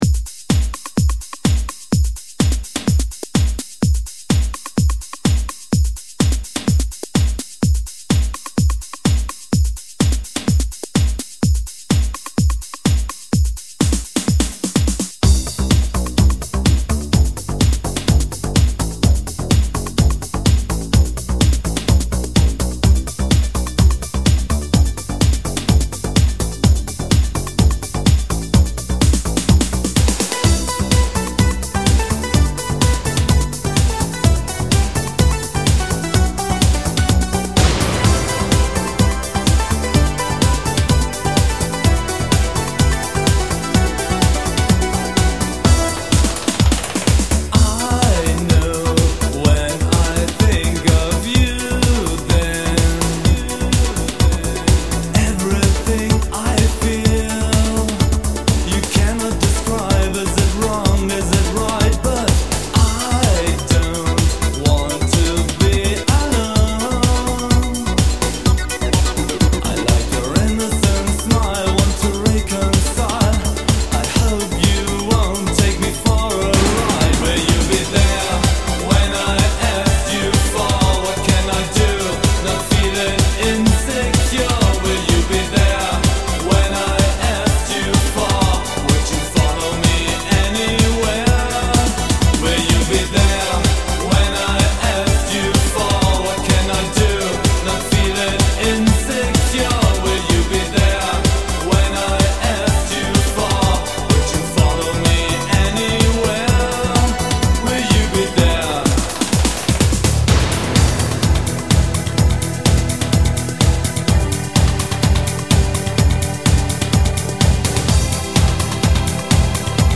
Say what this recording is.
Género: Synthpop.